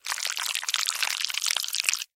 Звуки вязкости и липкости при погружении руки в мягкую массу